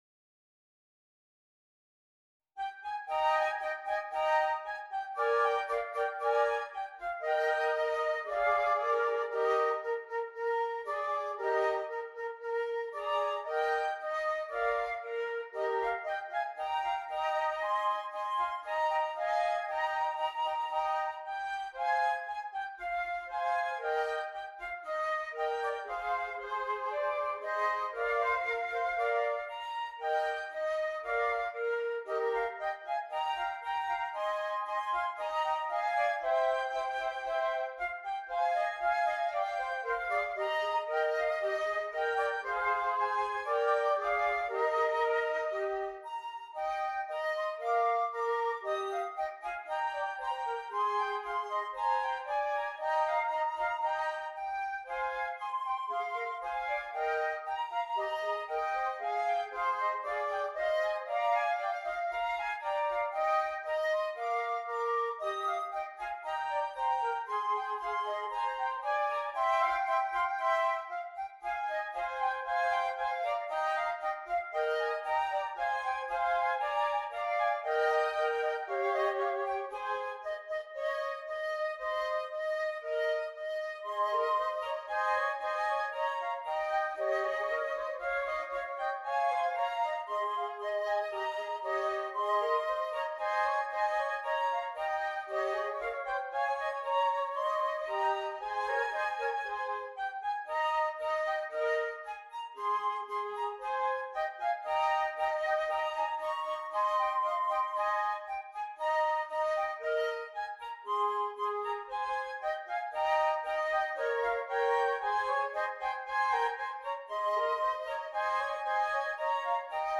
3 Flutes